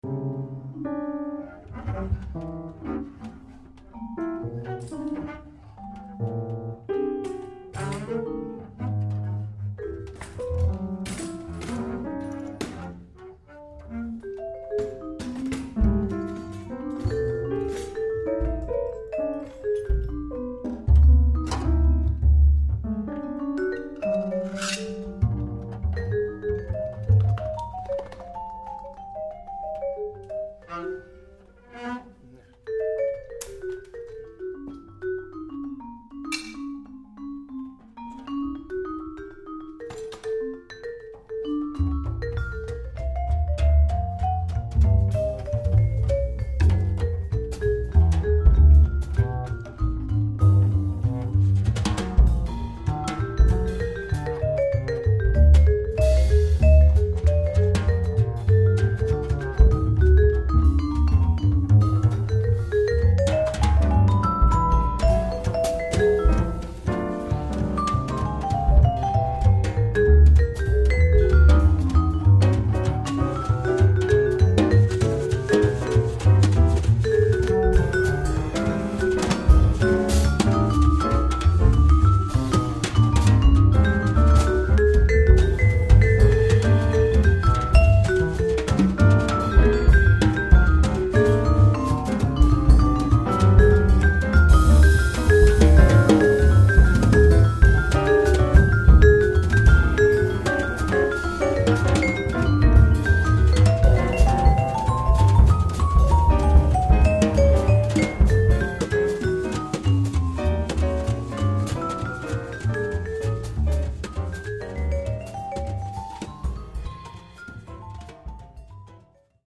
Piano
Vibrafon
Kontrabass
Schlagzeug, Spielsachen
Das mitreißende Spiel der freien Kräfte